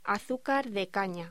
Locución: Azúcar de caña